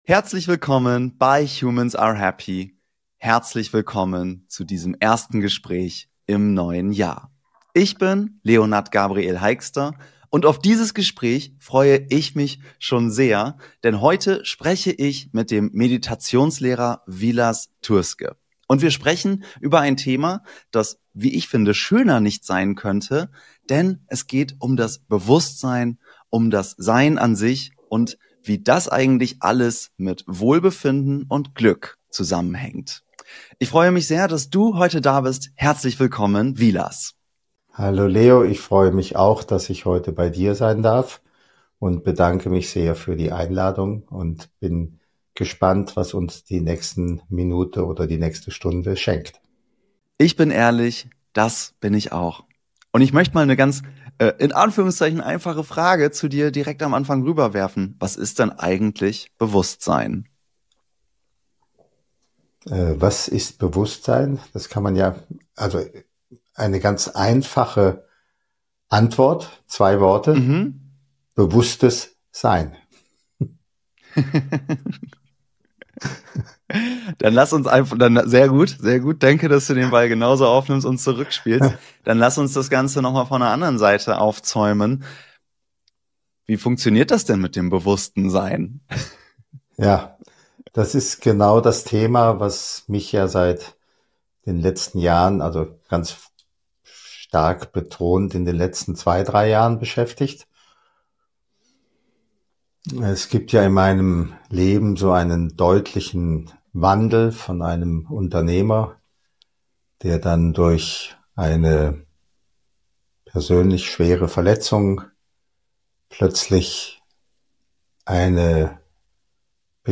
Diese Folge ist tief, sie ist weich, sie ist langsam, und sie ist ehrlich.